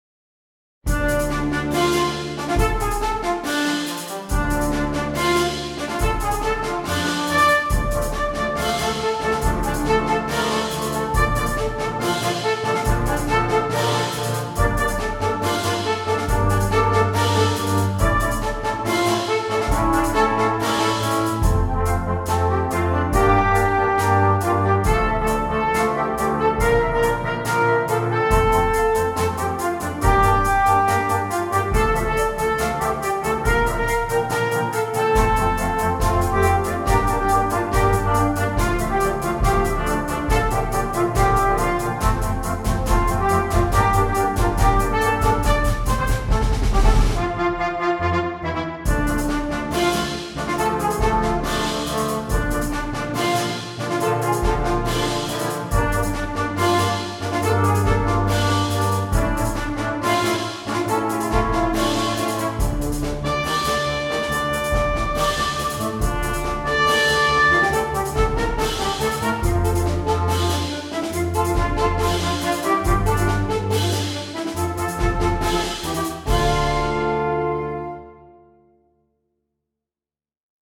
Virtual recordings were made using NotePerfomer 3.